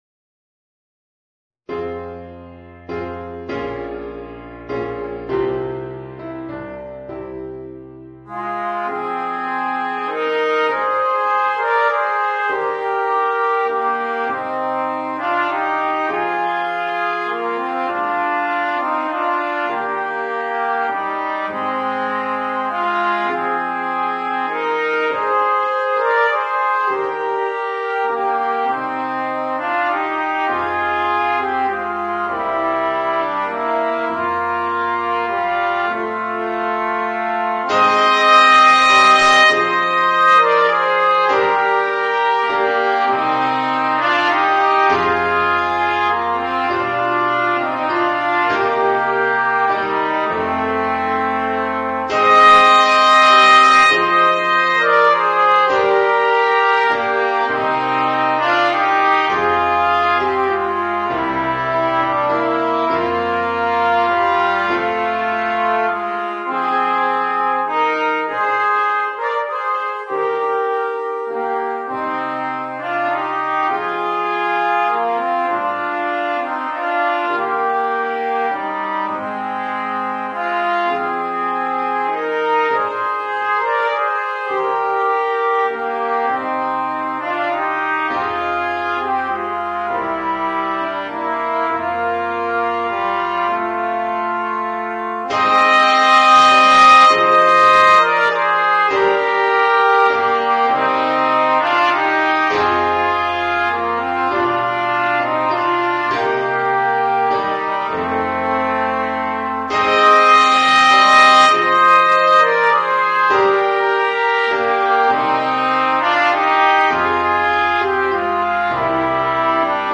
2 Trumpets